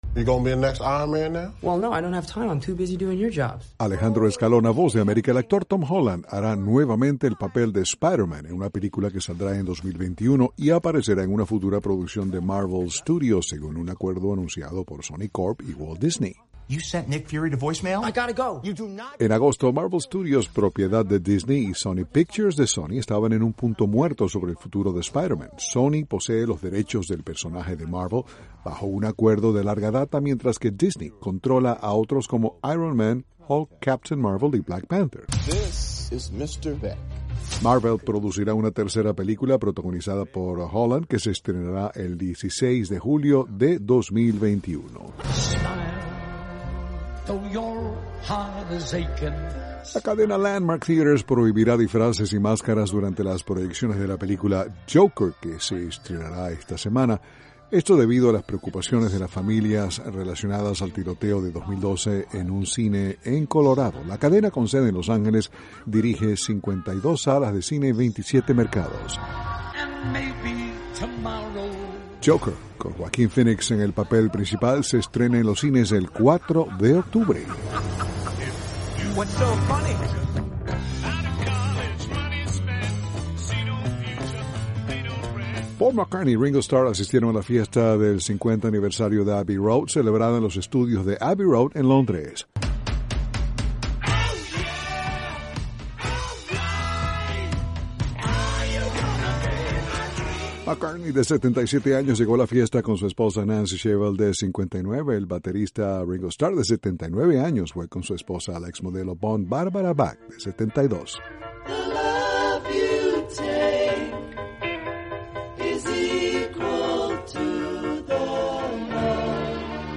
informa desde Washington...